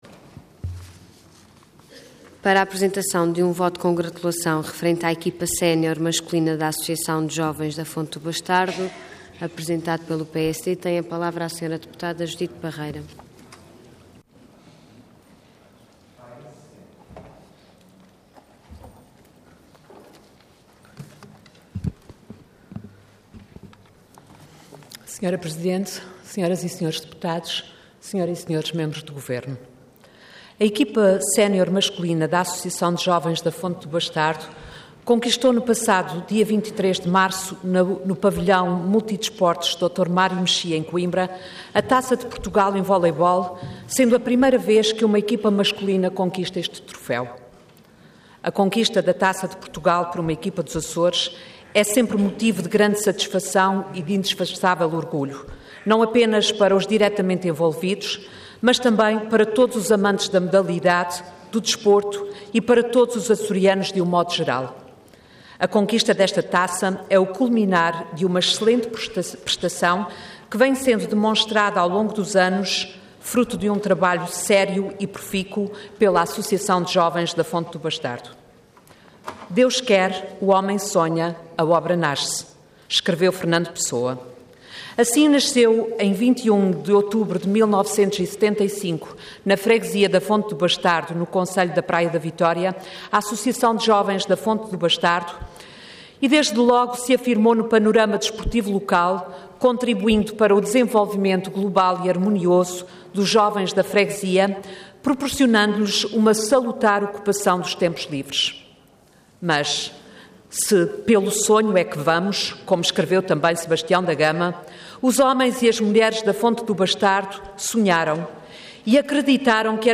Intervenção Voto de Congratulação Orador Judite Parreira Cargo Deputada Entidade PSD